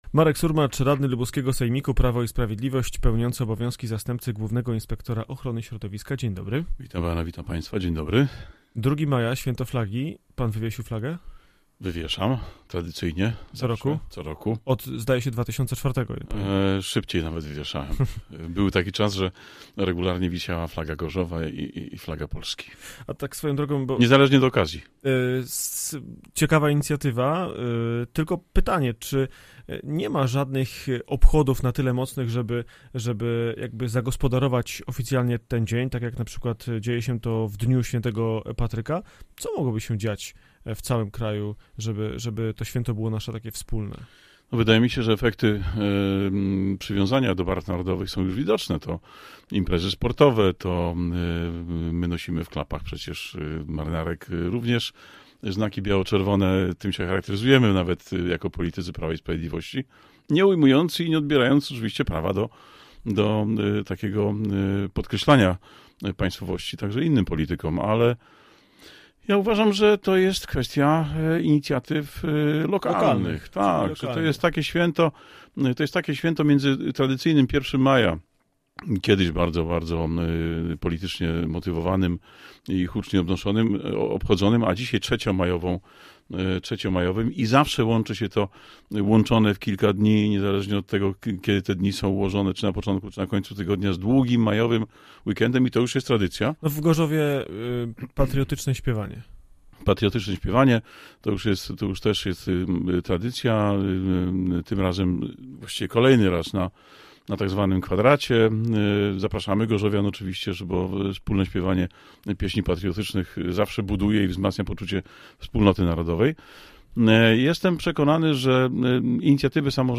Z radnym sejmiku (PiS) rozmawia